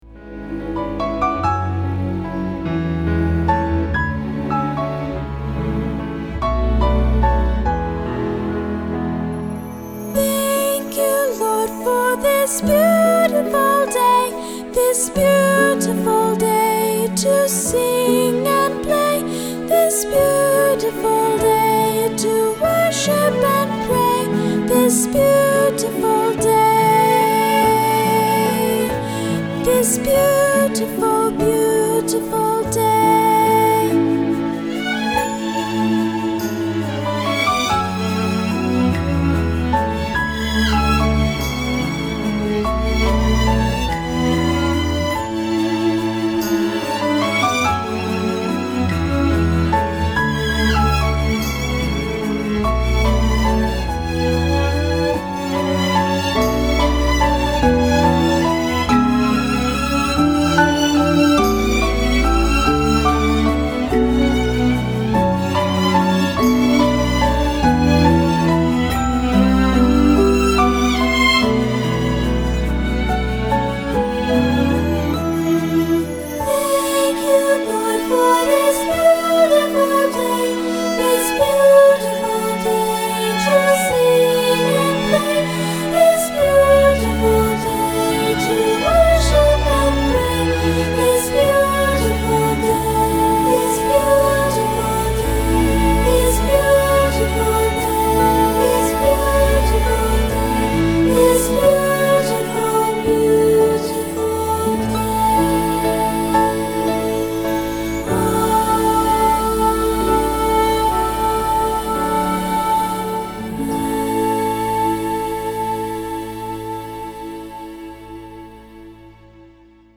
Unison with piano